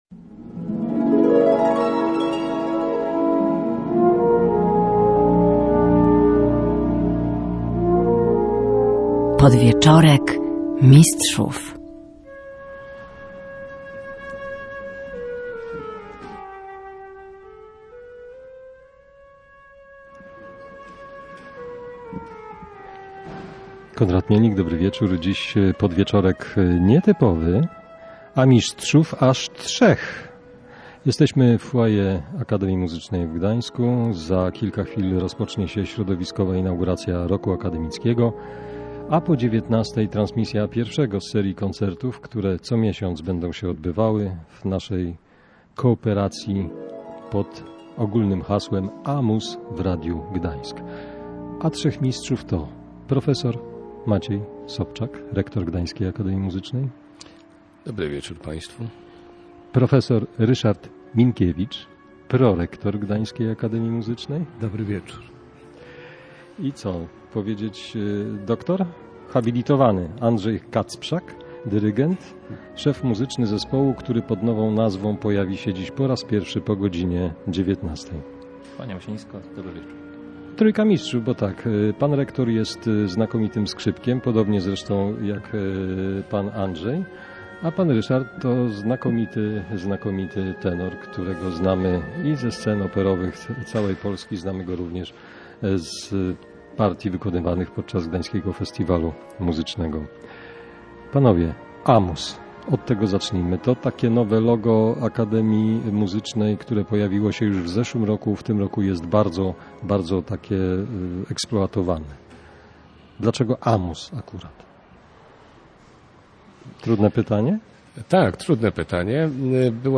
Podwieczorek mistrzów tym razem prosto z Akademii Muzycznej w Gdańsku z okazji koncertu inaugurującego cykl „aMuz w Radiu Gdańsk”. Trzech mistrzów gościło w tej odsłonie audycji.